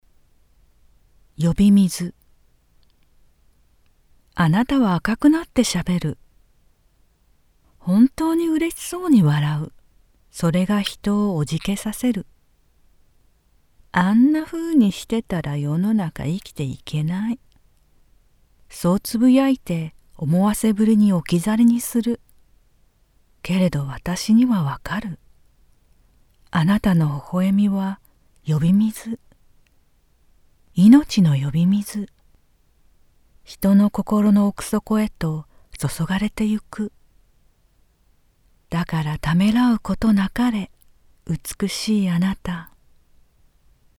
詩の朗読